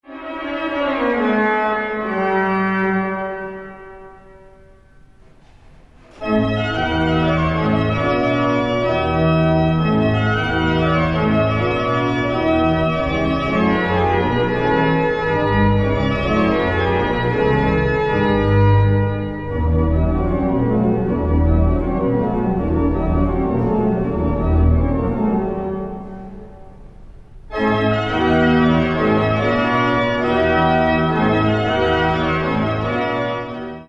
Classical, Organ